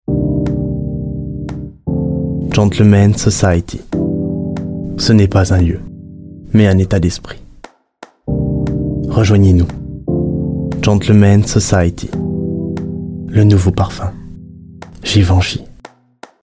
Voix off PUB parfum
17 - 30 ans - Baryton